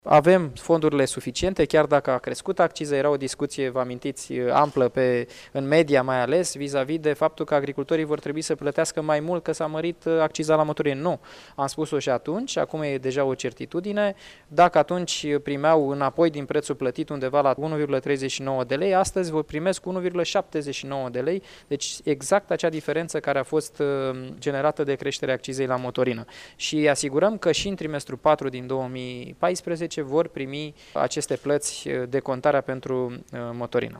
Ministrul agriculturii Daniel Constantin a mai declarat că decontările pentru motorina folosită în lucrările agricole nu vor fi influenţate de creşterile de accize la carburanţi.